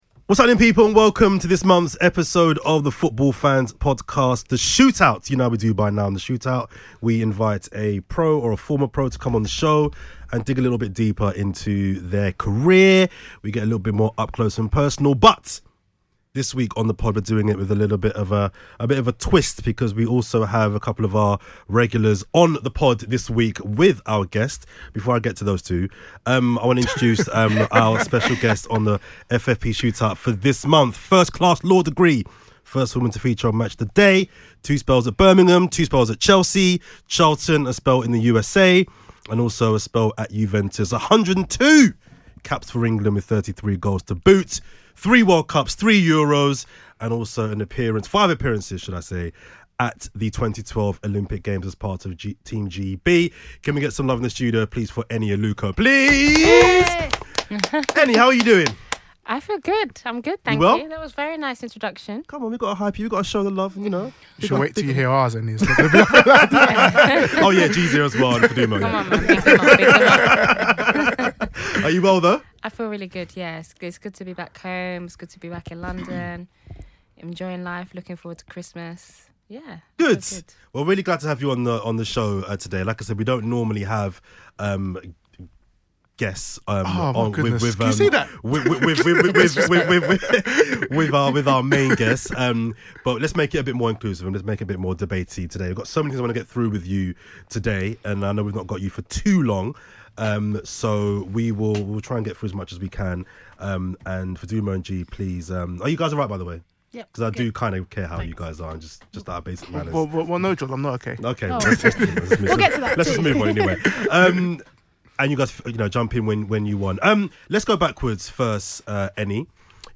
This month we speak to a legend of the women’s game, former Chelsea, Juventus, Birmingham, and England international, Eni Aluko.